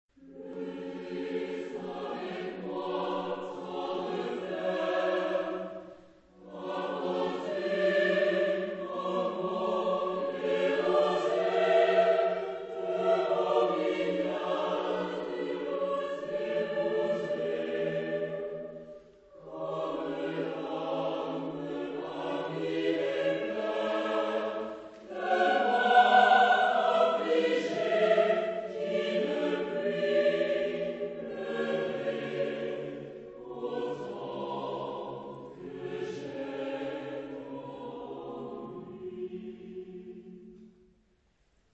Chansons et madrigaux, chœur à trois ou quatre voix mixtes et piano ad libitum